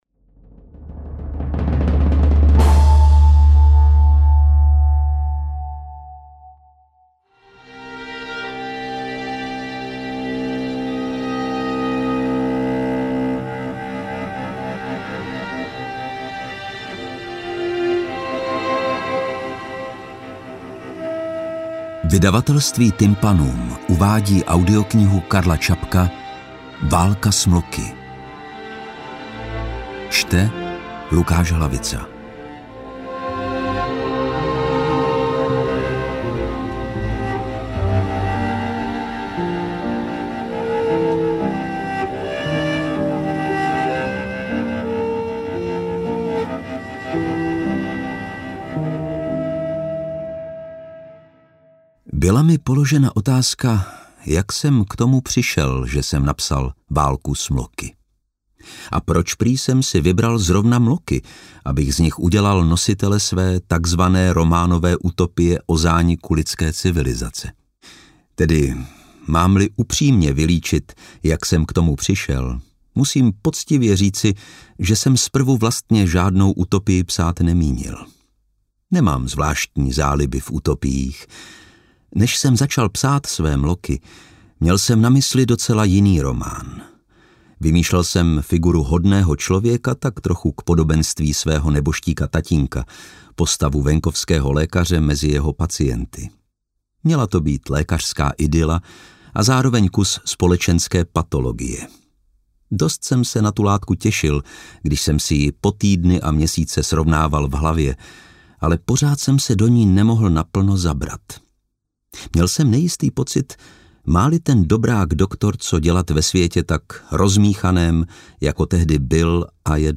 Interpret:  Lukáš Hlavica
Velmi příjemné zpracování klasického díla nadčasového charakteru.